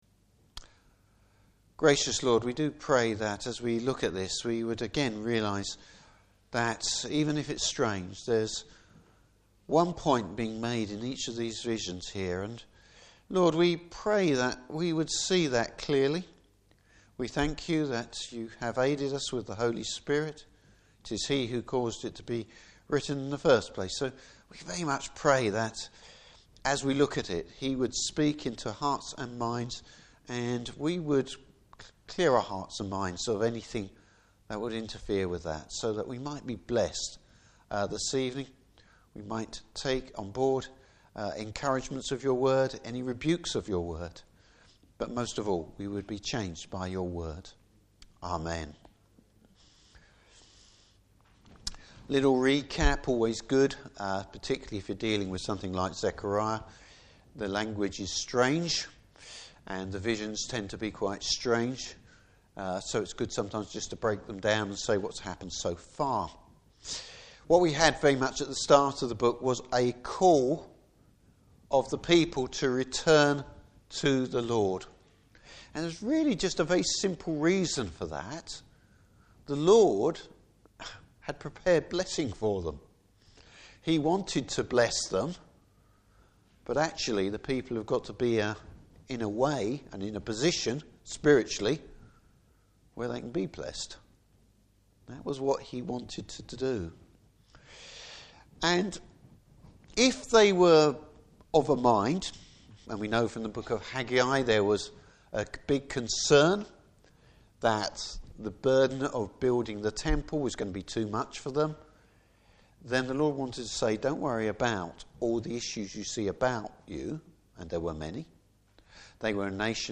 Service Type: Evening Service A demonstration of the Lord’s grace.